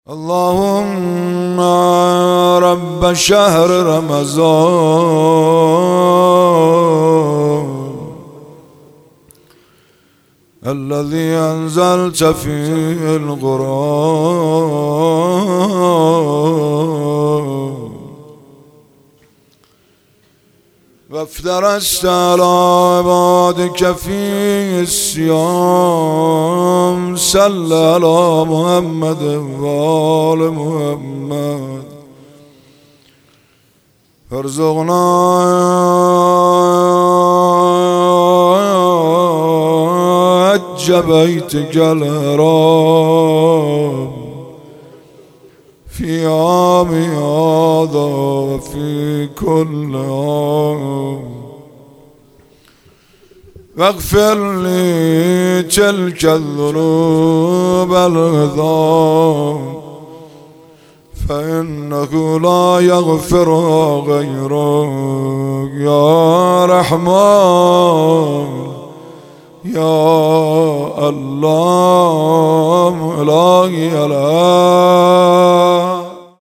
شب شانزدهم رمضان
مناجات